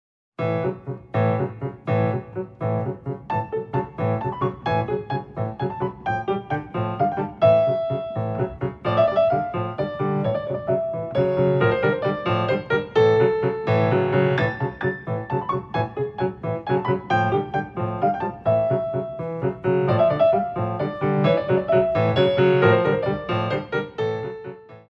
12x8 6/8